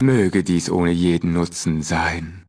Fallout 2: Audiodialoge